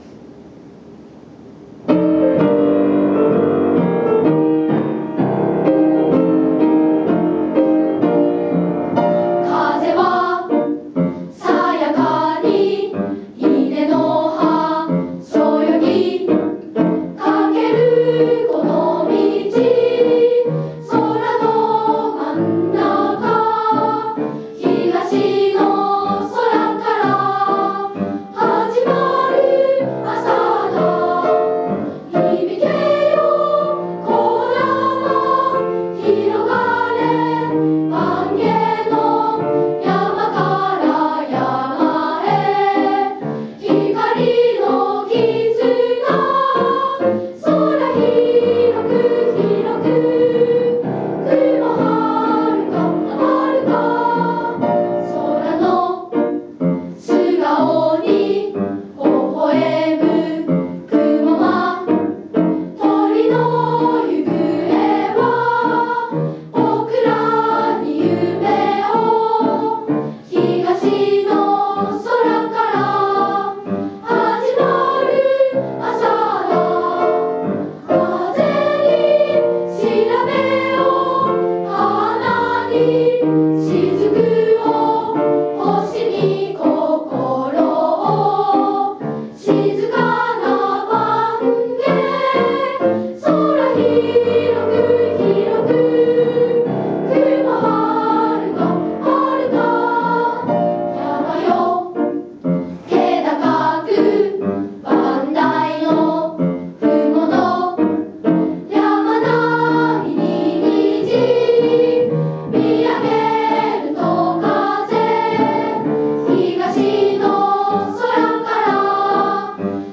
校歌・校章